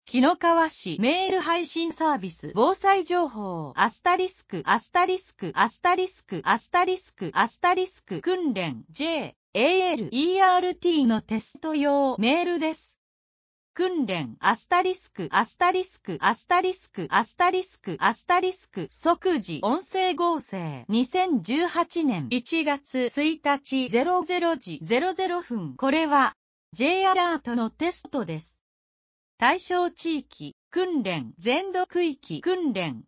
即時音声書換情報
【訓練】***** 「即時音声合成」 2018年01月01日00時00分 これは、Ｊアラートのテストです。